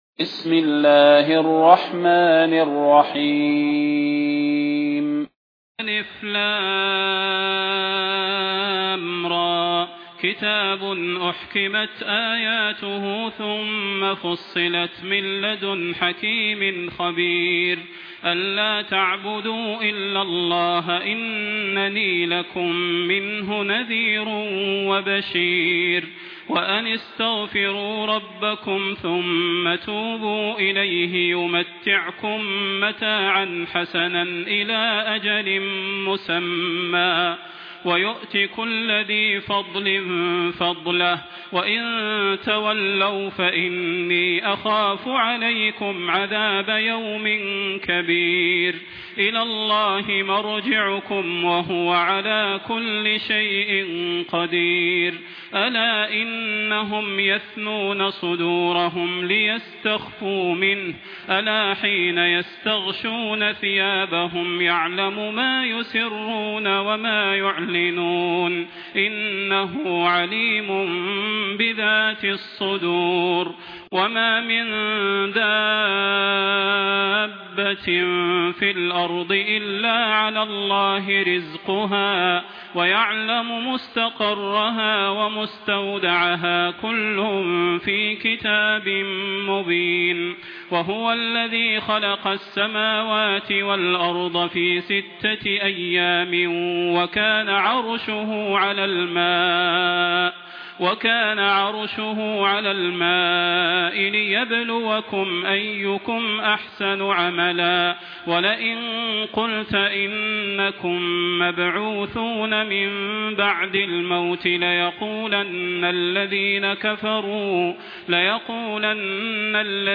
المكان: المسجد النبوي الشيخ: فضيلة الشيخ د. صلاح بن محمد البدير فضيلة الشيخ د. صلاح بن محمد البدير هود